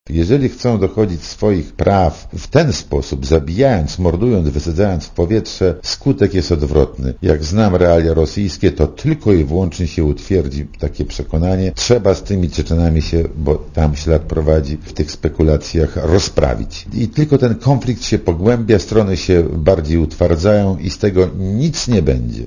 Nierozstrzygnięty konflikt etniczny jest - zdaniem byłego polskiego ambasadora w Moskwie Stanisława Cioska, przyczyną ataków terrorystycznych w Rosji. Ciosek nie wiąże piątkowego zamachu ze zbliżającymi się wyborami prezydenckimi. To przejaw próby dochodzenia do swoich praw przez zdesperowanych ludzi – powiedział Radiu Zet Stanisław Ciosek.
Dla Radia Zet mówi Stanisław Ciosek (82 KB)